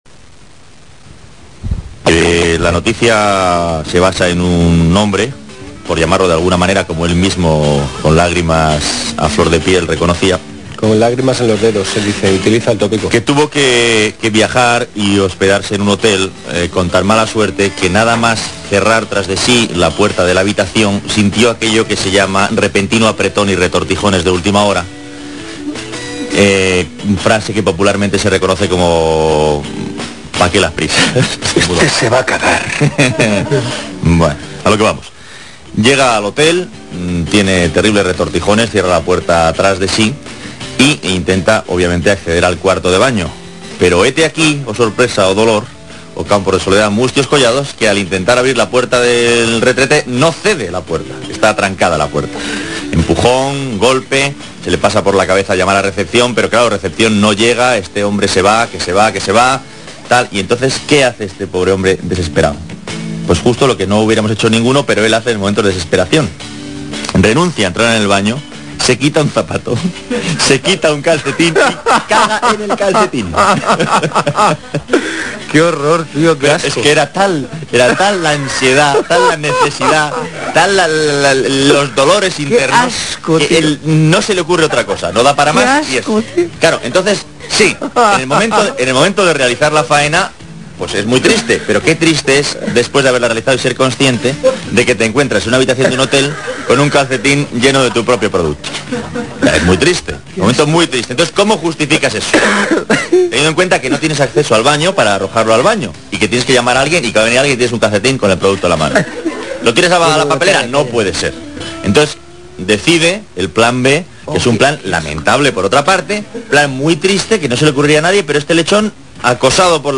Y bueno, mejor que lo oigáis directamente del programa de radio de Gomaespuma de donde está extraído.